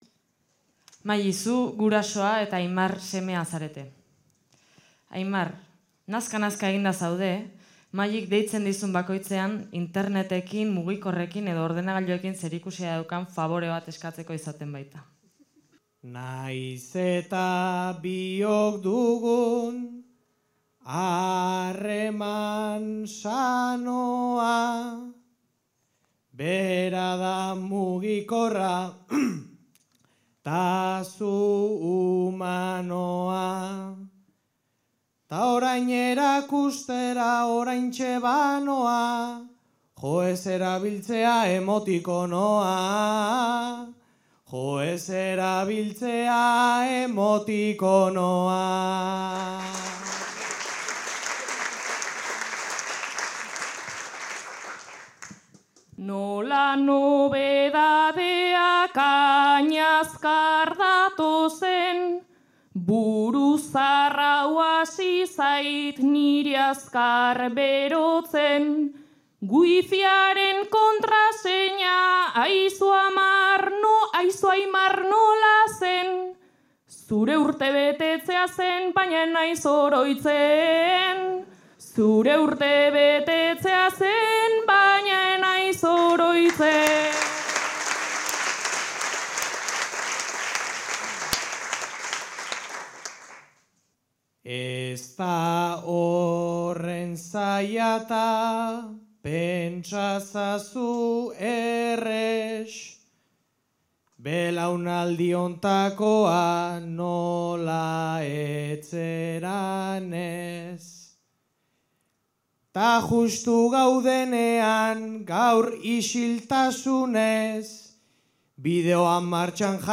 Zarautz (Gipuzkoa)
Zortziko txiikia.